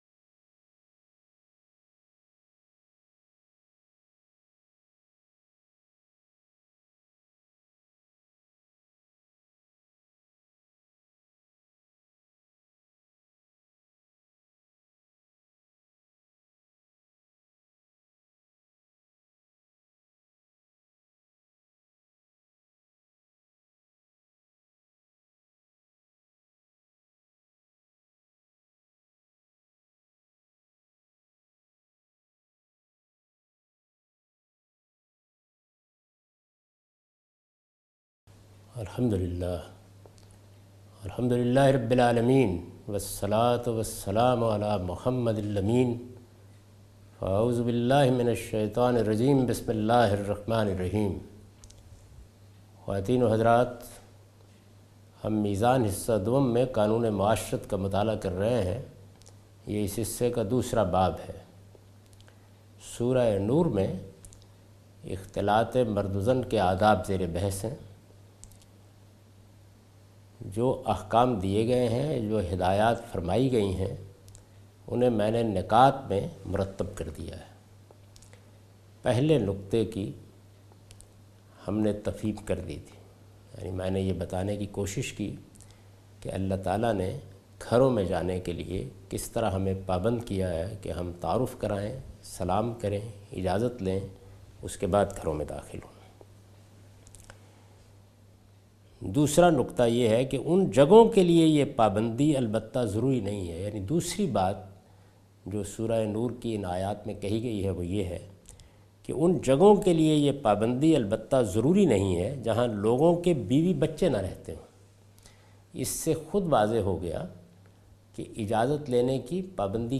A comprehensive course on Islam, wherein Javed Ahmad Ghamidi teaches his book ‘Meezan’.
In this lecture he teaches norms of gender interaction in Islam.